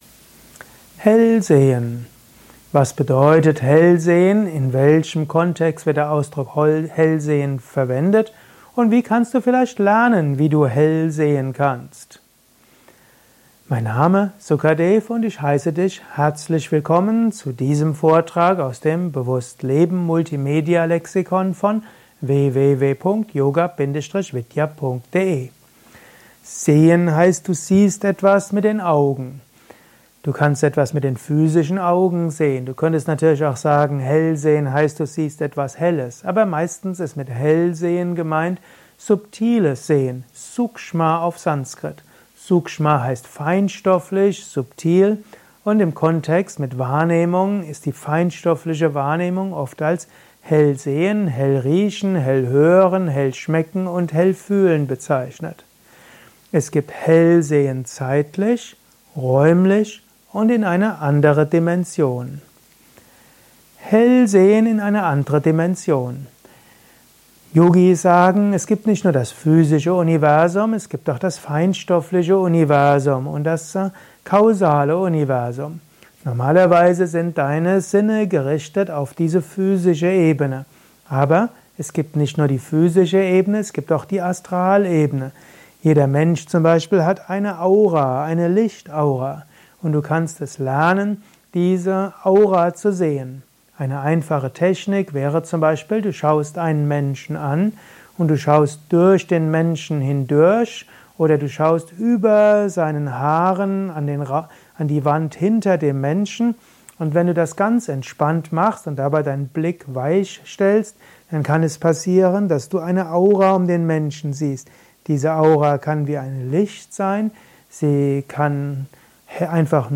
Ein kurzer Audiovortrag